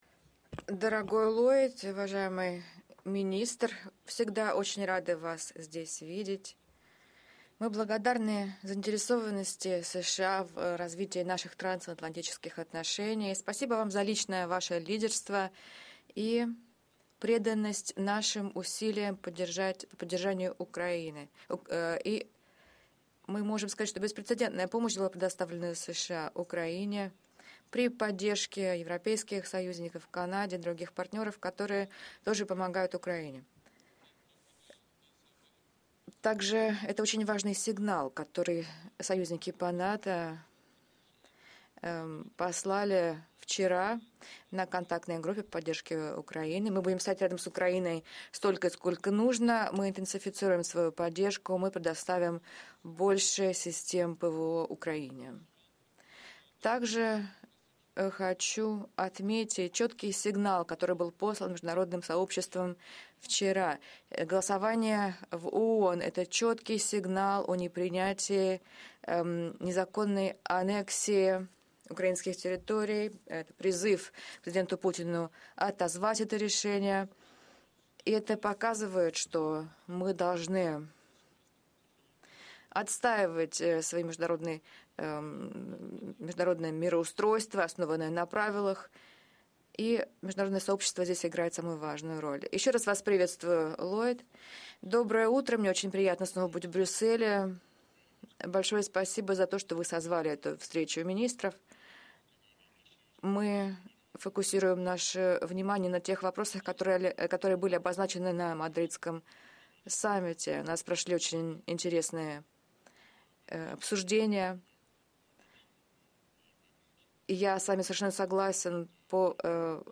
ENGLISH - Opening remarks by NATO Secretary General Jens Stoltenberg at the first session of the Meeting of the North Atlantic Council in Defence Ministers’ session